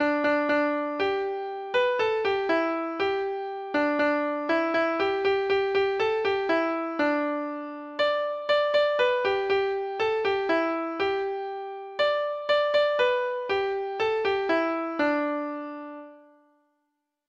Folk Songs